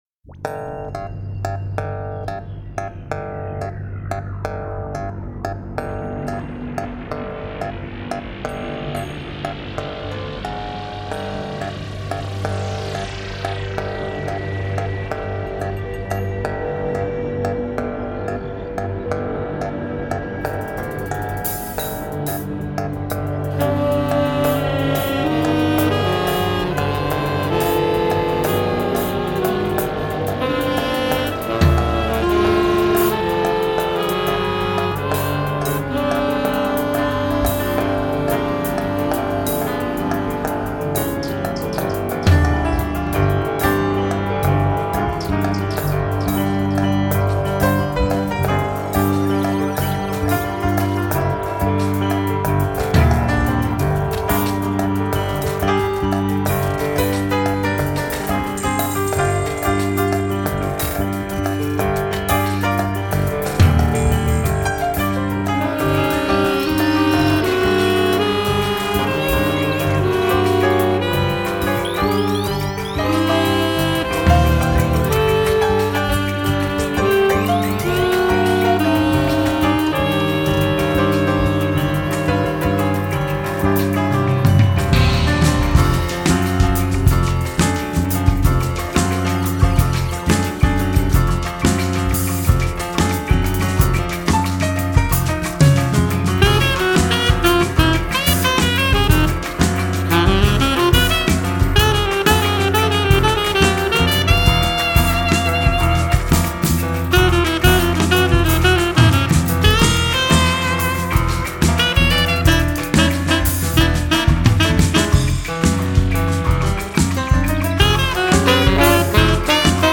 Saxophones
Piano and Electronics
Bass
Drums